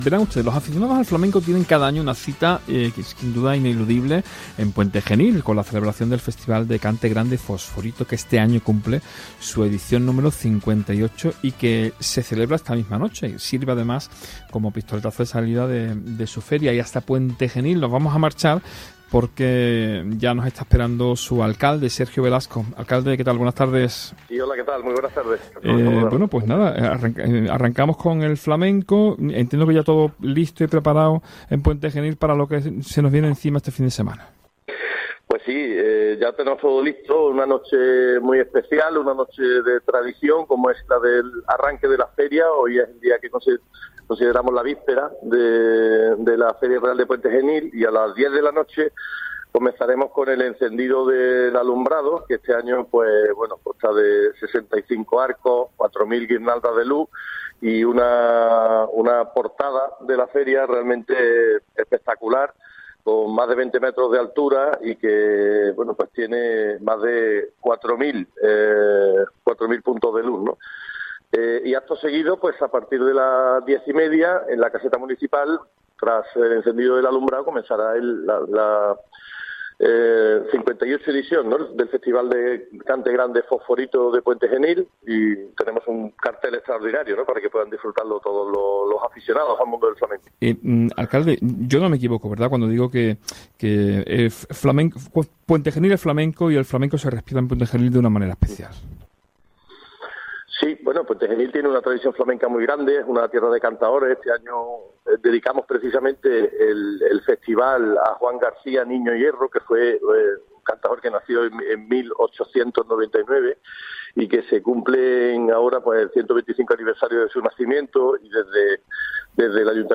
ENTREVISTA | Sergio Velasco, alcalde de Puente Genil - Andalucía Centro
ENTREVISTA | Sergio Velasco, alcalde de Puente Genil